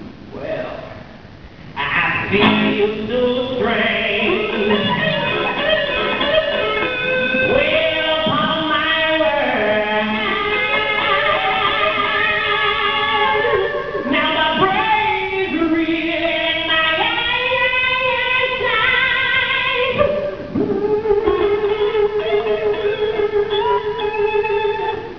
Live Sound Clips from "Grease'